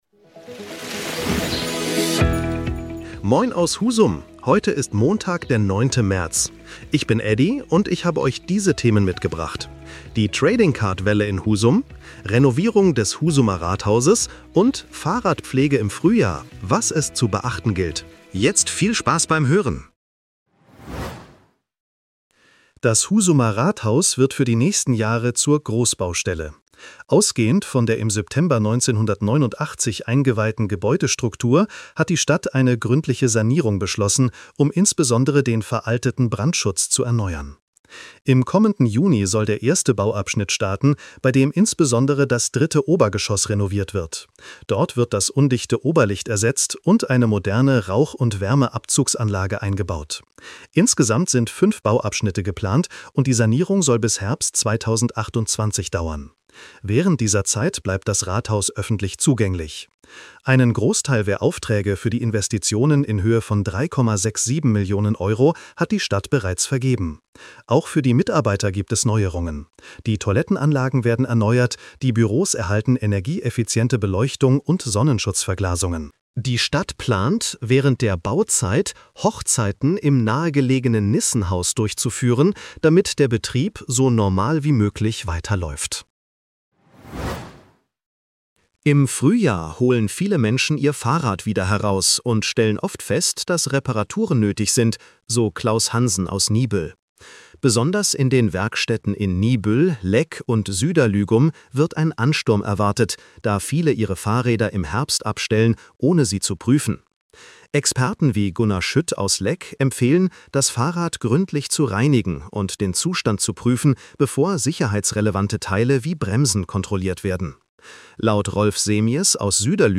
Was bewegt Husum heute? In unserem regionalen Nachrichten-Podcast